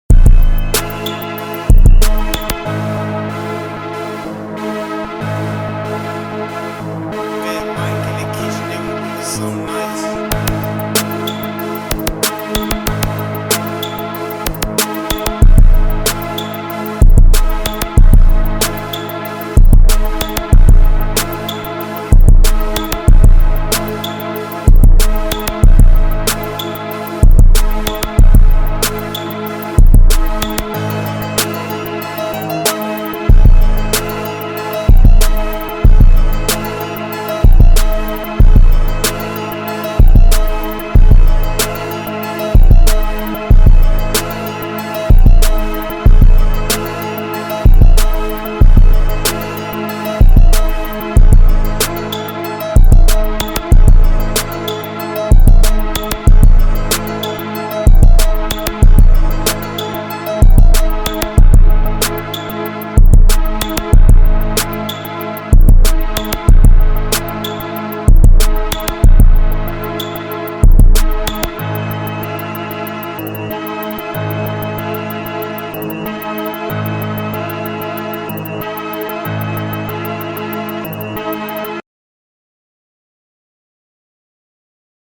2024 in Detroit Instrumentals